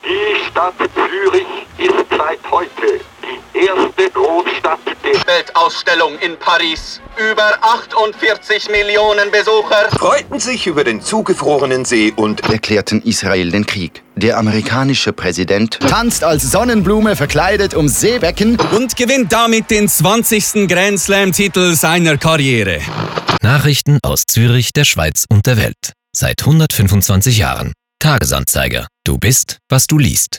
Die Kampagne umfasst fünf Sujets und wird von zwei Radiospots ergänzt, welche die Tagesaktualität und thematische Breite der Zeitung humorvoll dramatisieren. Im Stile collageartiger Nachrichtenmeldungen wechseln die Sprecher mitten im Satz das Thema und den dazugehörigen Tonfall – von schneidend zu schwärmend, von nüchtern zu euphorisch – und streichen so hervor, dass man im «Tagi» über alle Lebensbereiche umfassend informiert wird.